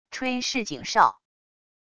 吹示警哨wav音频